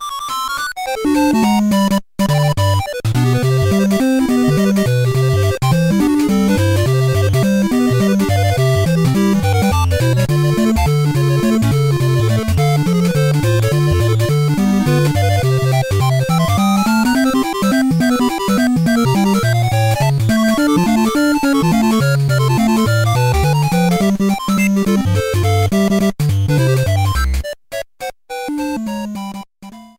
Started partway through the track and fadeout
Fair use music sample